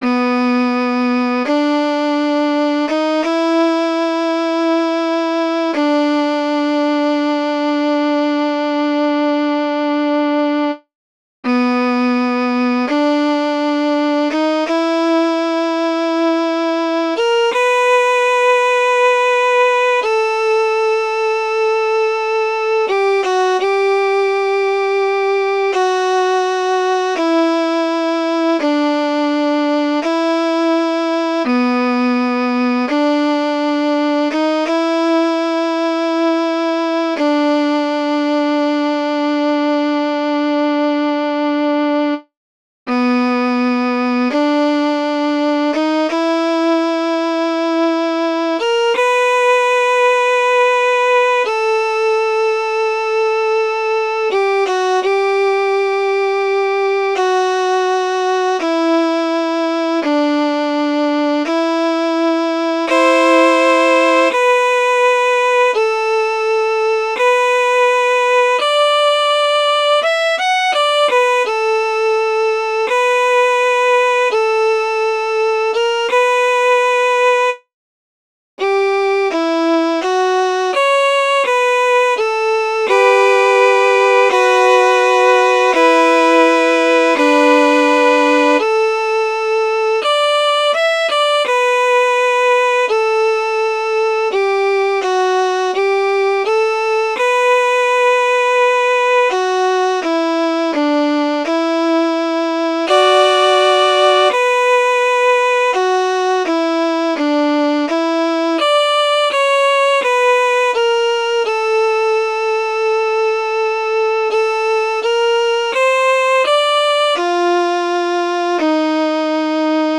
Another (Partial) Attempt of writing an Instrumental
Here is another attempt of writing an instrumental.
IT is very dramatic.
I'd love to hear someone play it on violin to see if it sounds as good in a regular setting as a computer generated one.